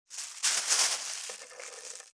615コンビニ袋,ゴミ袋,スーパーの袋,袋,買い出しの音,ゴミ出しの音,袋を運ぶ音,
効果音